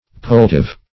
poultive - definition of poultive - synonyms, pronunciation, spelling from Free Dictionary Search Result for " poultive" : The Collaborative International Dictionary of English v.0.48: Poultive \Poul"tive\, n. A poultice.